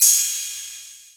Cymbal 2.wav